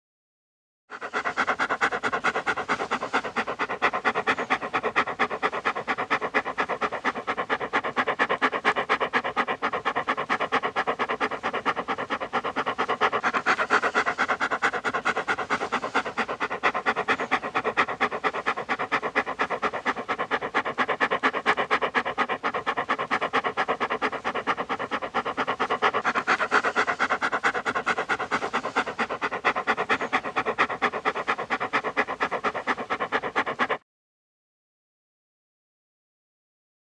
Dog, Large; Pants, Very Rapid, Close Perspective.